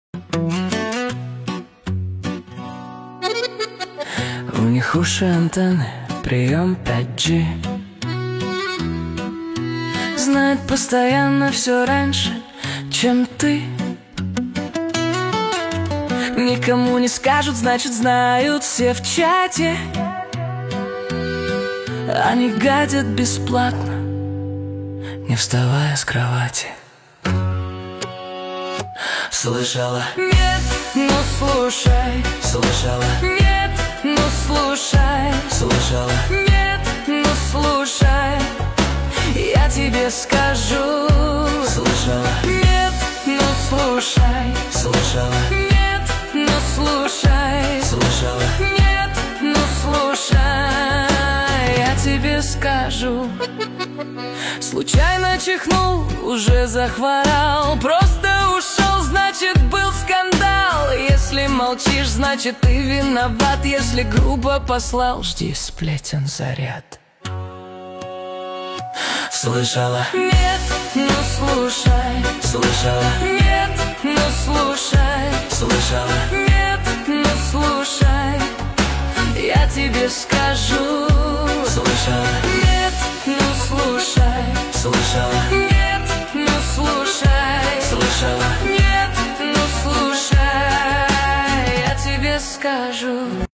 Русская AI музыка